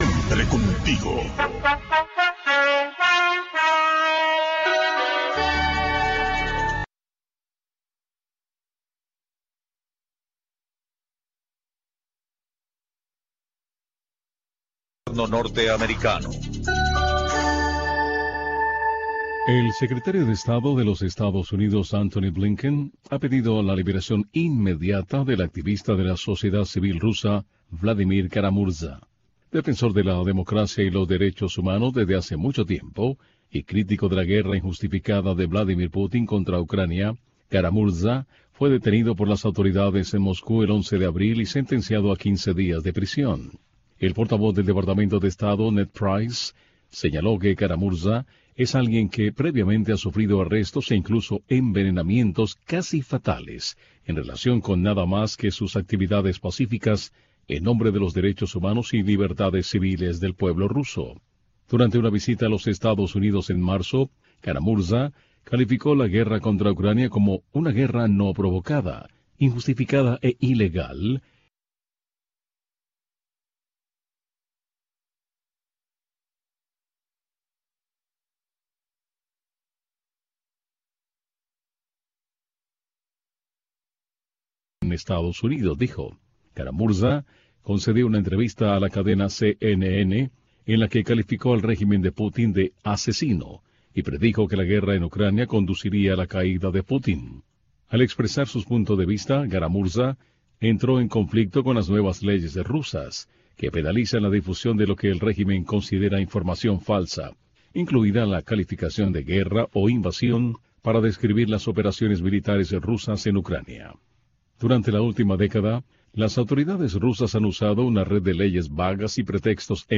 PROGRAMACIÓN EN-VIVO DESDE LA ERMITA DE LA CARIDAD